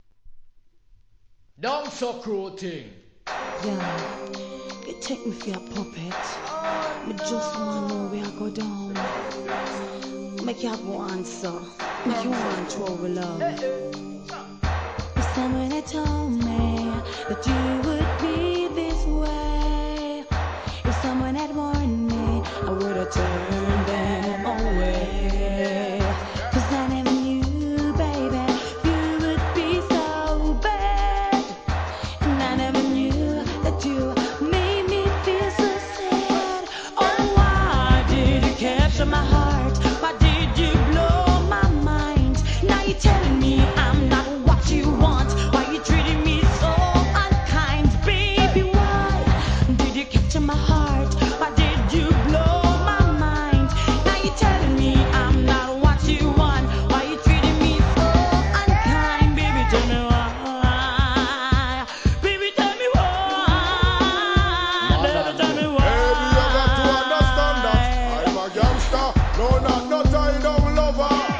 REGGAE
NICE VOCAL!!!